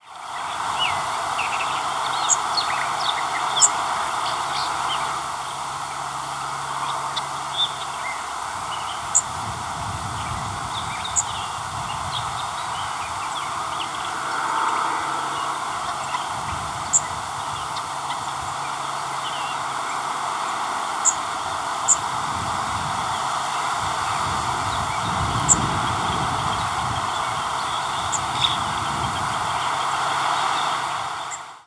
Magnolia Warbler diurnal flight calls
Diurnal calling sequences: